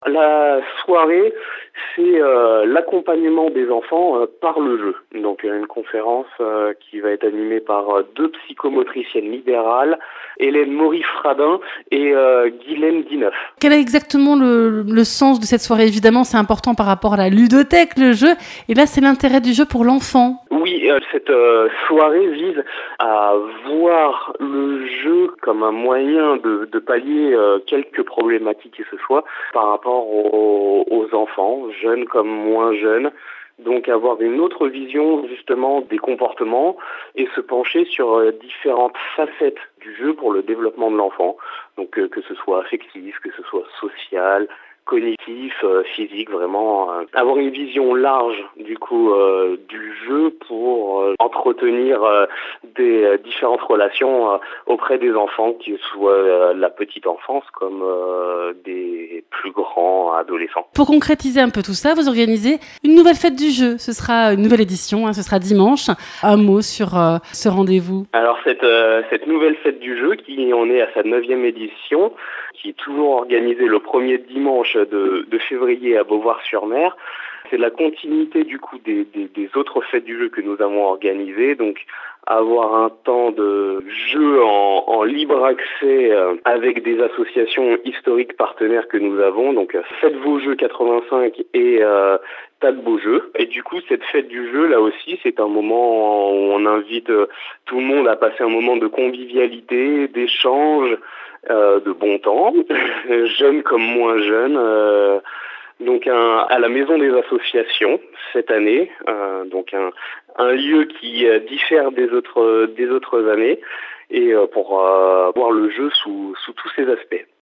Les Reportages de NOV FM